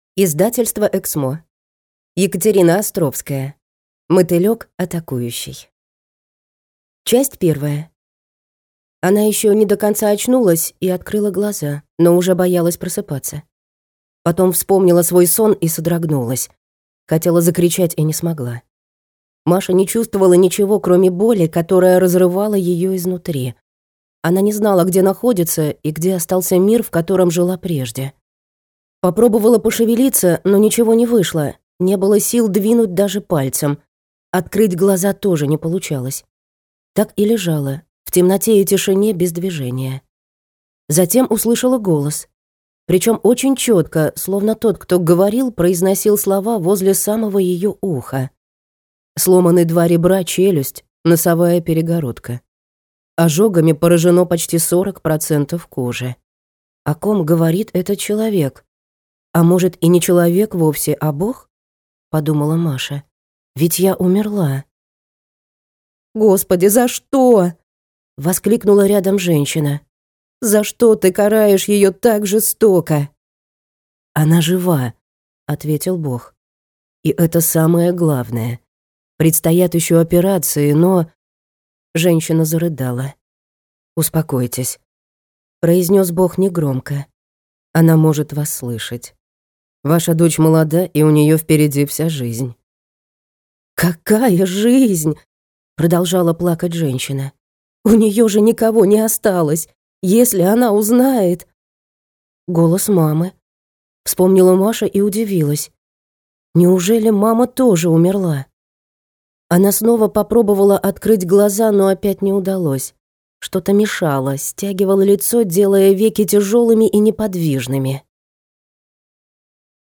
Аудиокнига Мотылек атакующий | Библиотека аудиокниг